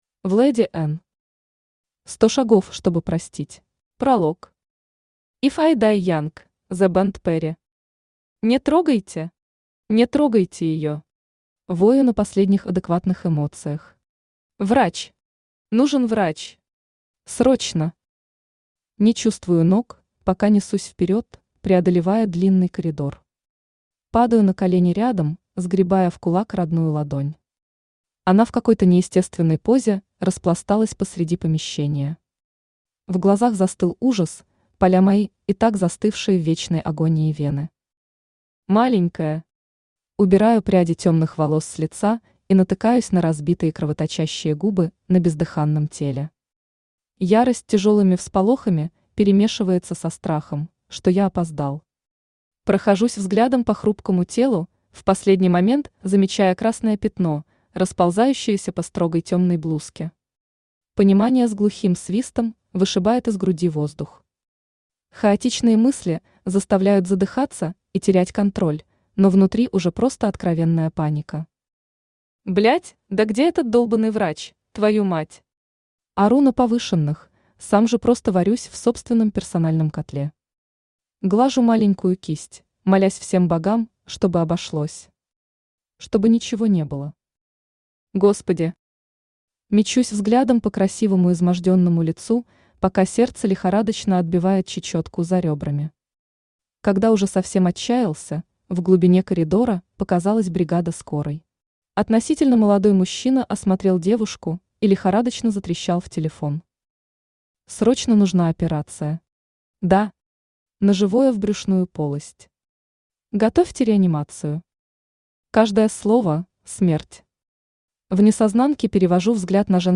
Аудиокнига Сто шагов, чтобы простить | Библиотека аудиокниг
Aудиокнига Сто шагов, чтобы простить Автор Vladi N Читает аудиокнигу Авточтец ЛитРес.